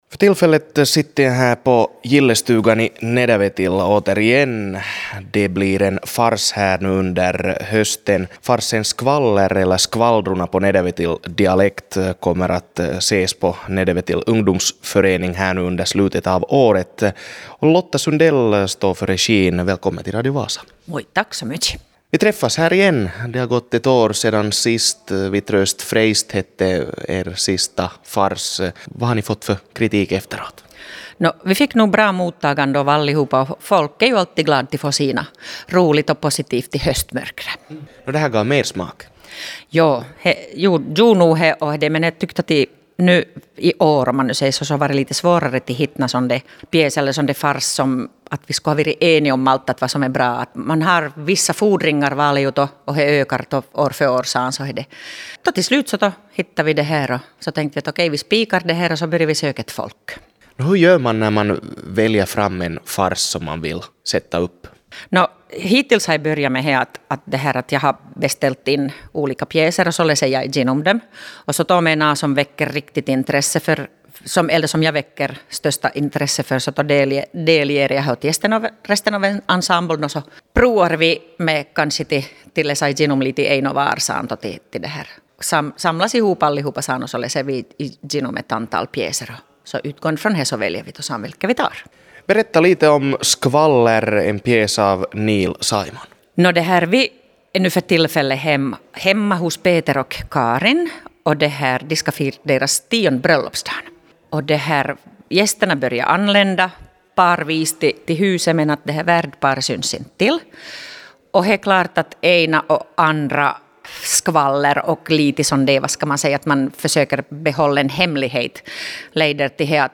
Nedervetil är känd för sin kännspaka dialekt ”Grundsprååtsi”. Nedervetil teater sätter upp farsen Skvaller eller ”Skvalldrona” på dialekt efter fjolårets succé med ”Vi trööst freist”.